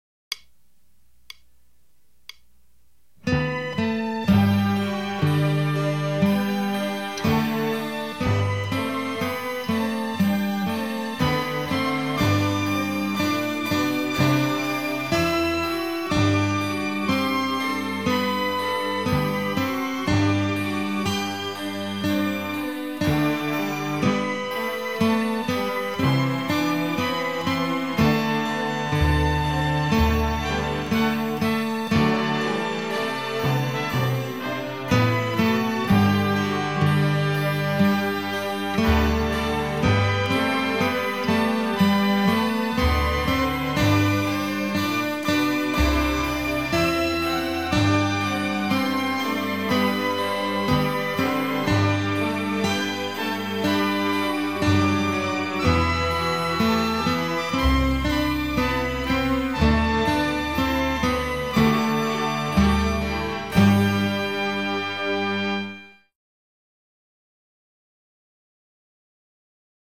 Gitar çalmak istiyorsanız işinize yarayacaktır.